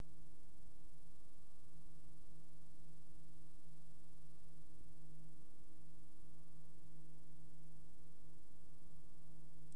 Minimale Umdrehung von 1100 U/min
enermax_warp_80_min.wav